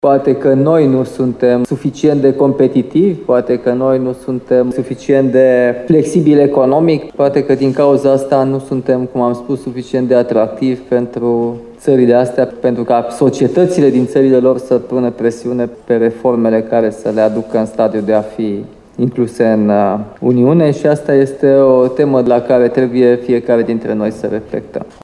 În discursul său, președintele Nicușor Dan s-a întrebat care este motivul pentru care cetățenii din țările care ar putea intra în UE, dar nu au aderat încă, nu pun mai multă presiune pe decidenții politici pentru a face pasul spre integrare.